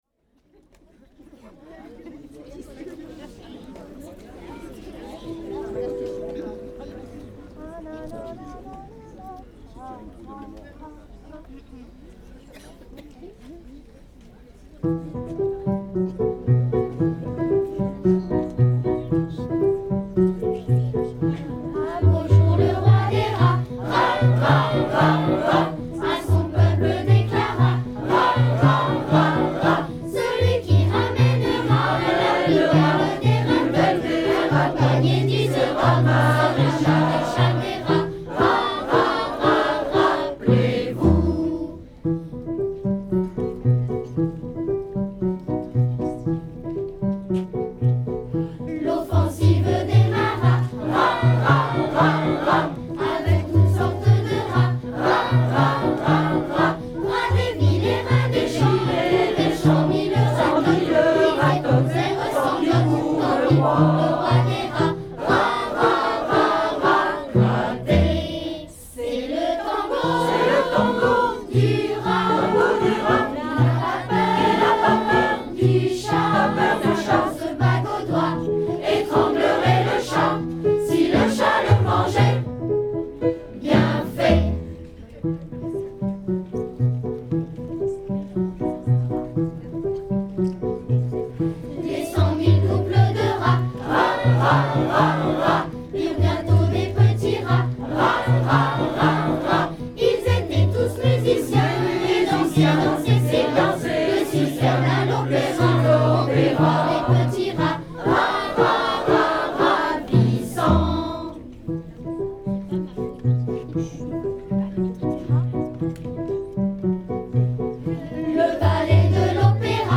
Fête de la musique 2014